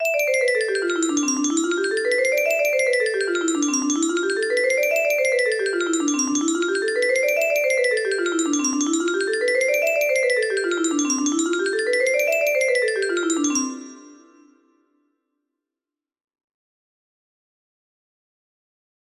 Up and down test music box melody